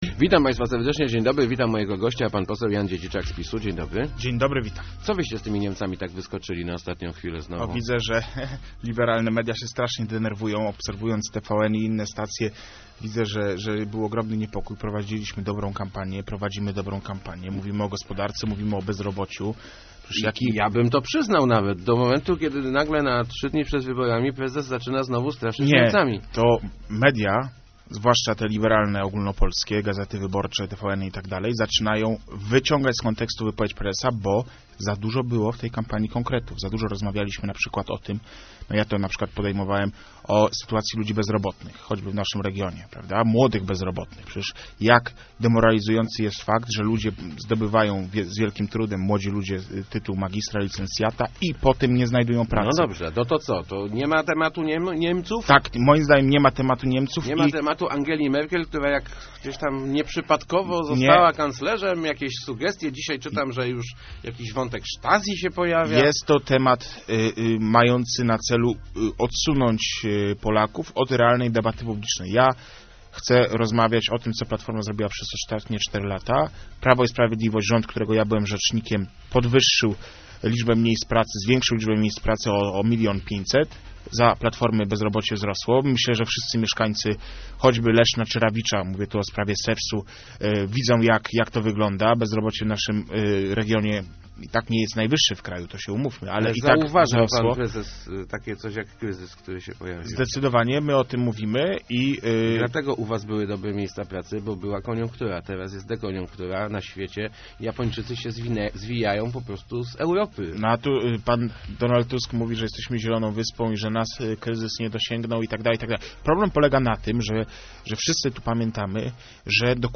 Jeżeli PiS odzyska władzę, S-5 będzie budowana w pierwszej kolejności - zapewniał w Rozmowach Elki poseł Jan Dziedziczak z PiS. Obiecuje on też rezygnację z innych pomysłów PO - sześciolatków w szkołach, 23-procentowego VATu i likwidacji 69 pułku.